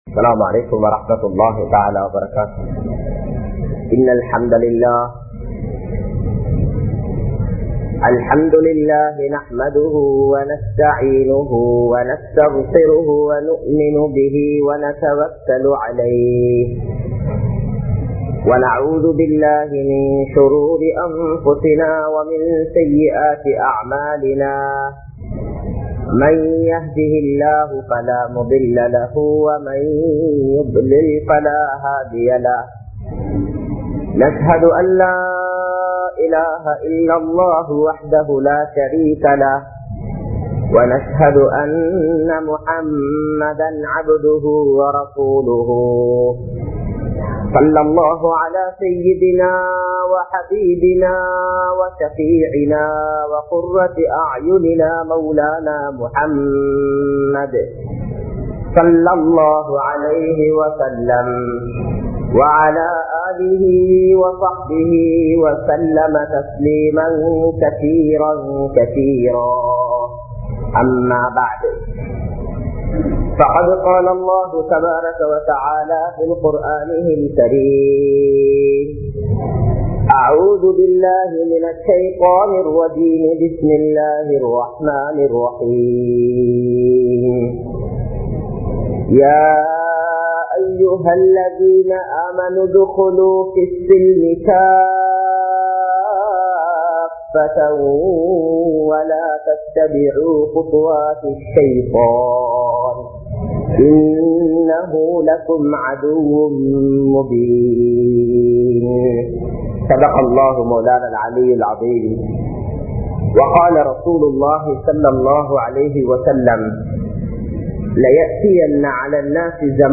Matravarkalin Hithayathukku Paadupaduvom(மற்றவர்களின் ஹிதாயத்துக்கு பாடுபடுவோம்) | Audio Bayans | All Ceylon Muslim Youth Community | Addalaichenai
Majma Ul Khairah Jumua Masjith (Nimal Road)